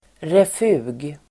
Ladda ner uttalet
refug substantiv, (traffic) island Uttal: [ref'u:g] Böjningar: refugen, refuger Definition: upphöjning i gata som väntplats för fotgängare (also "refuge") island substantiv, refug Variantform: även traffic island, även safety island [amerikansk engelska]